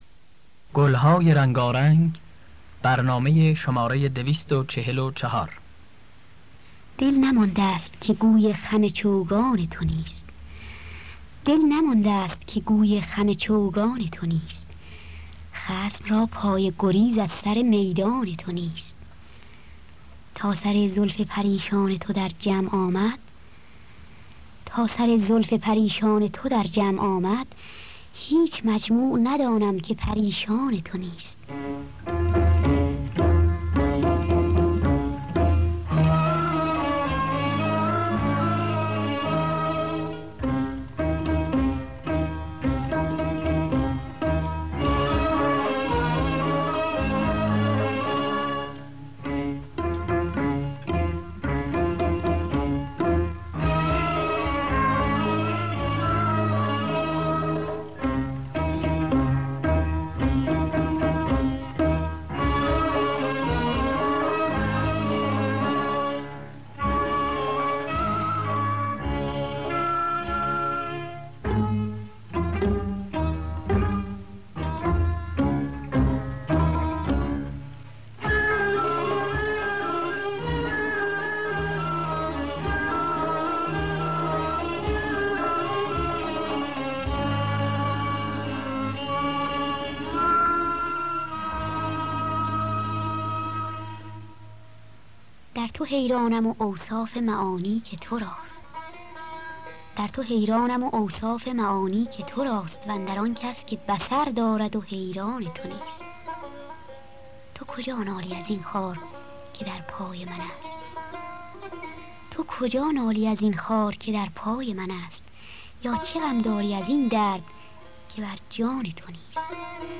در دستگاه همایون